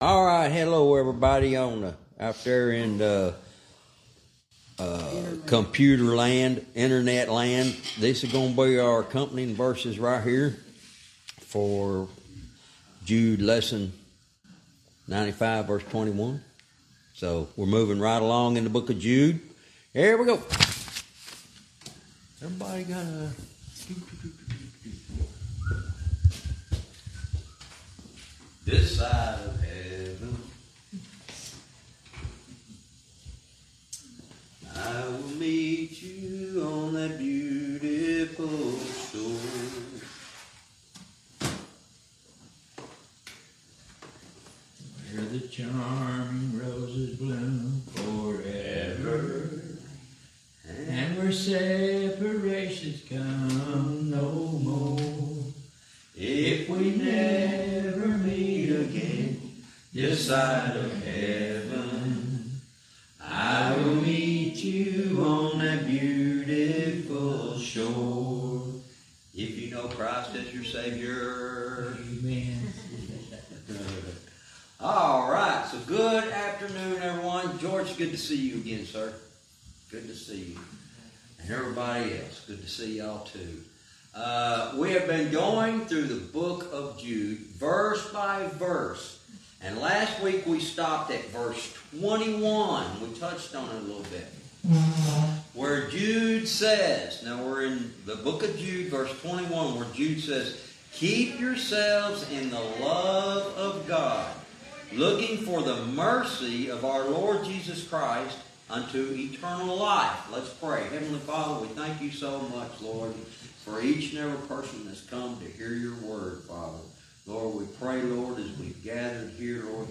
Verse by verse teaching - Jude lesson 95 verse 21